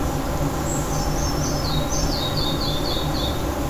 малая мухоловка, Ficedula parva
СтатусСлышен голос, крики